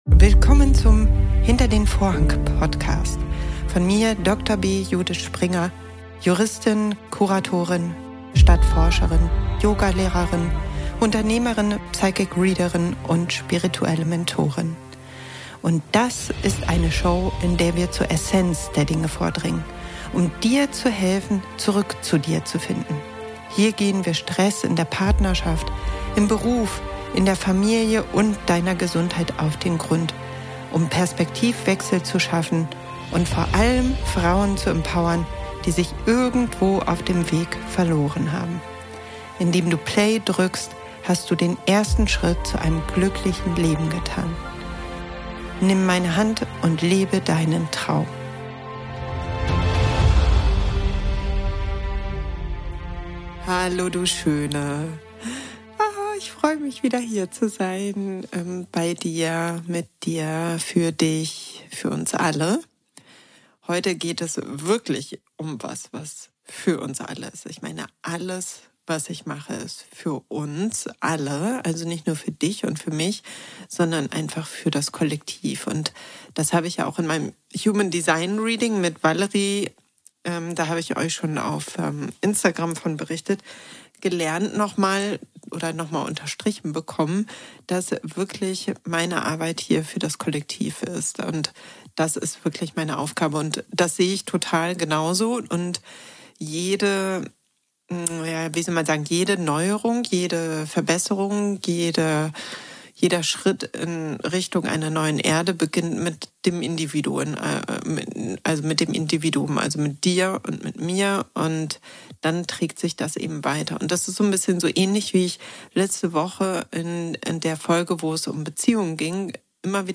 Puh, da wurde ich mal wieder sehr emotional...